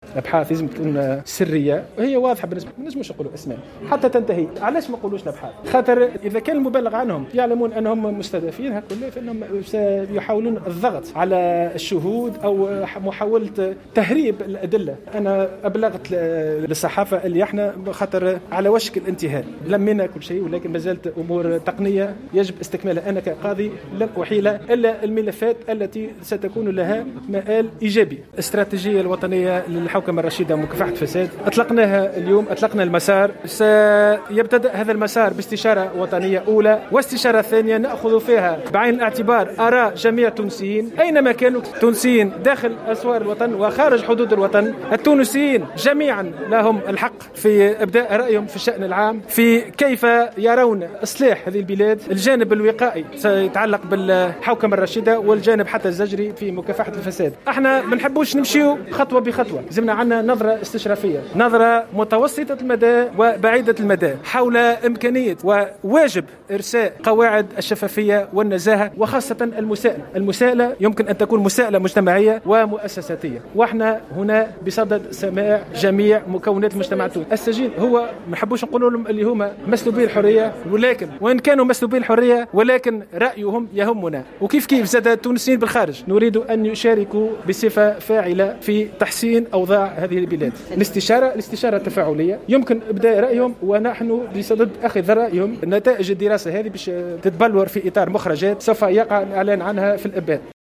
وأوضح بوخريص، في تصريح صحفي اليوم الجمعة، على هامش الإعلان عن انطلاق مسار الاستراتيجية الوطنية للحوكمة الرشيدة ومكافحة الفساد 2022-2026 بمقر الهيئة بالعاصمة، أن الكشف عن مزيد من المعطيات حول المعنيين بهذا الملف قد يدفعهم إلى الضغط على الشهود أو تهريب الأدلة التي تدينهم في حال شعروا أنهم مستهدفون.